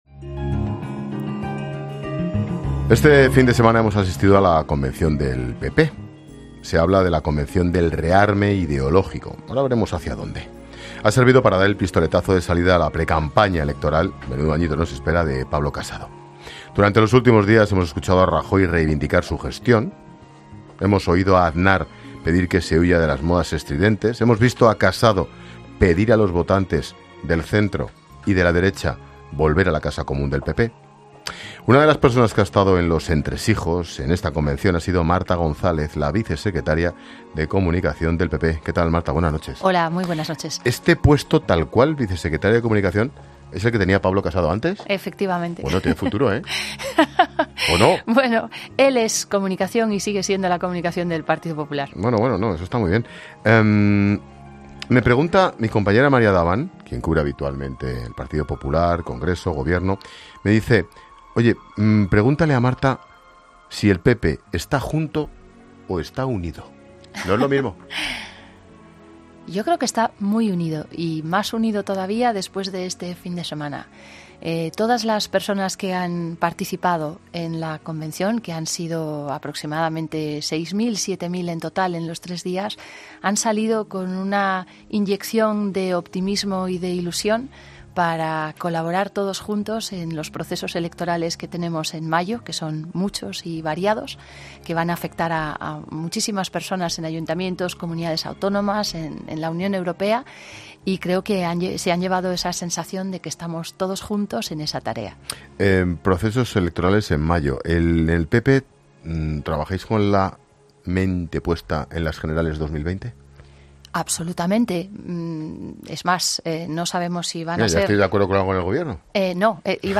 La vicesecretaria de comunicación del PP ha pasado por los micrófonos de 'La Linterna' para hacer balance de lo ocurrido de la convención de su partido